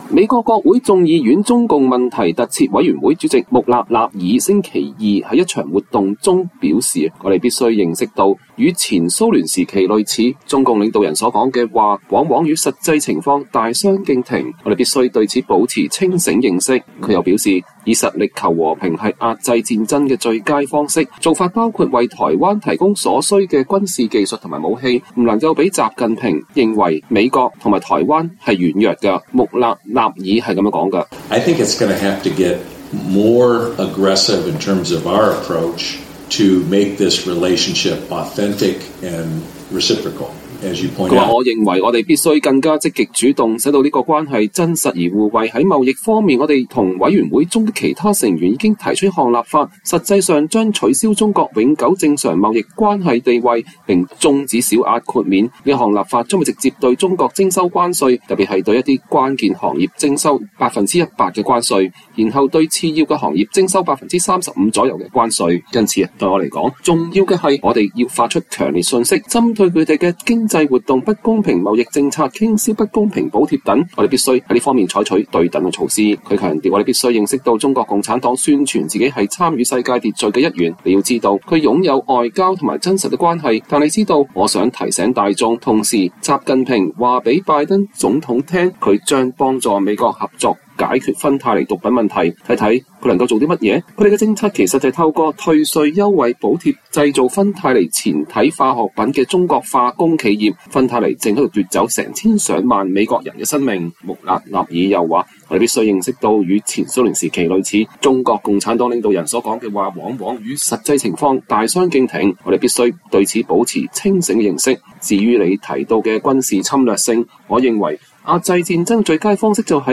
美國國會眾議院中共問題特設委員會主席穆勒納爾(John Moolenaar)星期二在一場活動中表示，“我們必須認識到，與蘇聯時期類似，中共領導人所說的話往往與實際情況大相徑庭，我們必須對此保持清醒認識”。